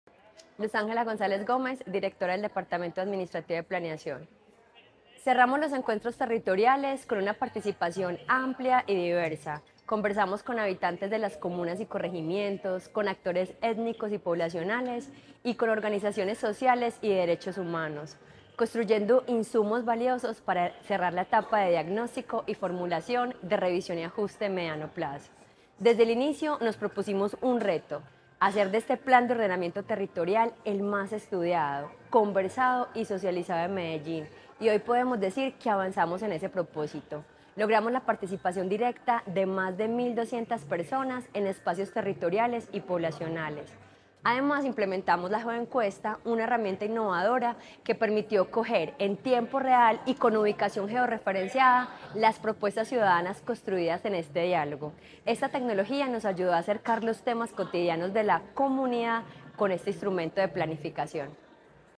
Declaraciones de la directora del Departamento Administrativo de Planeación, Luz Ángela González Gómez
Declaraciones-de-la-directora-del-Departamento-Administrativo-de-Planeacion-Luz-Angela-Gonzalez-Gomez-1.mp3